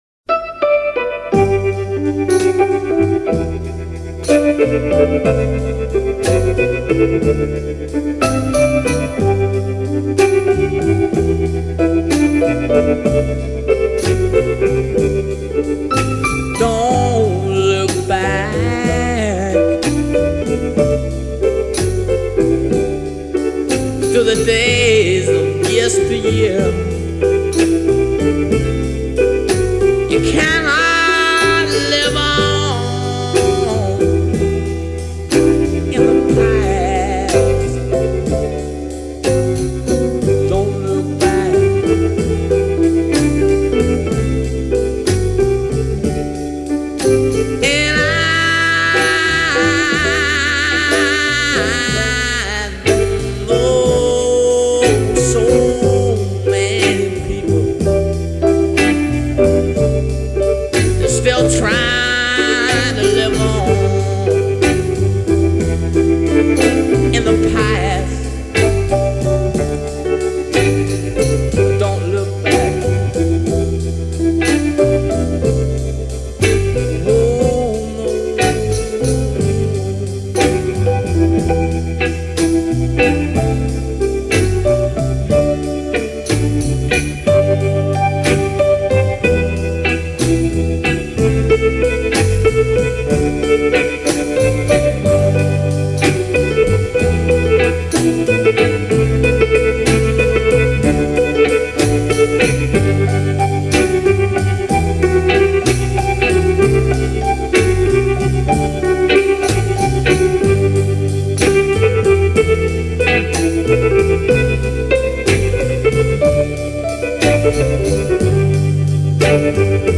a garage-rock/blues band from Belfast